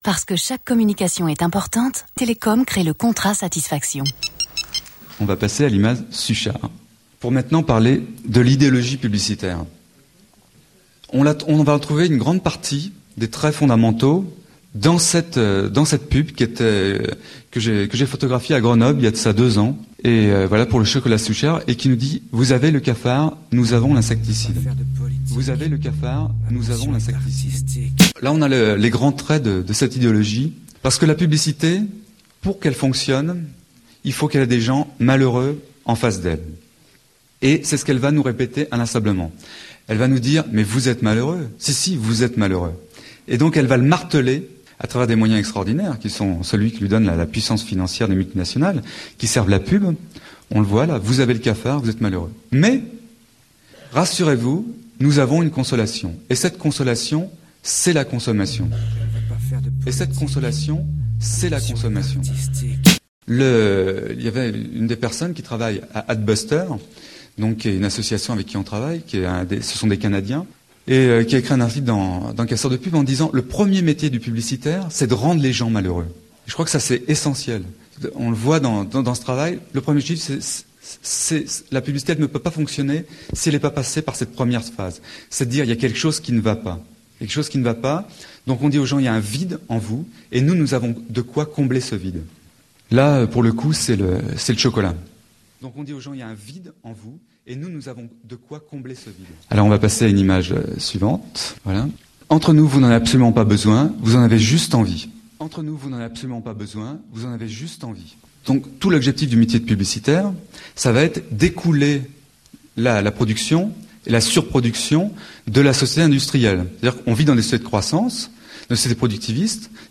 Un reportage sur la pub